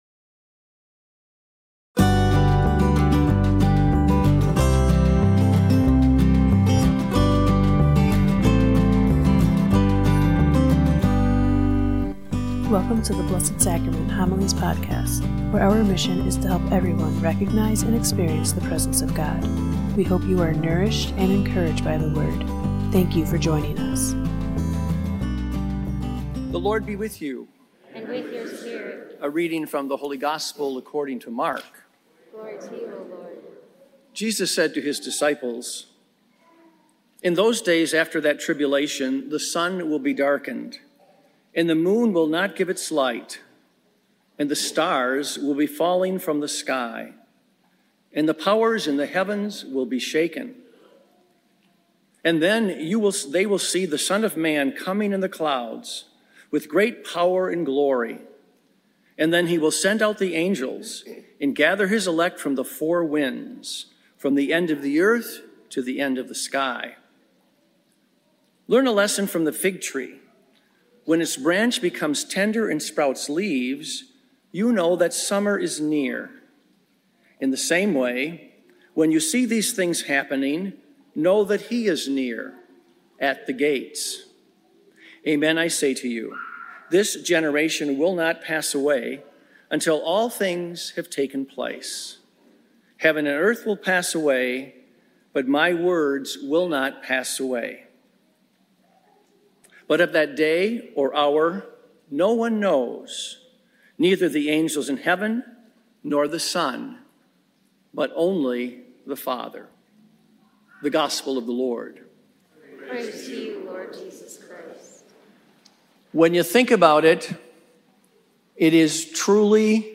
Blessed Sacrament Parish Community Homilies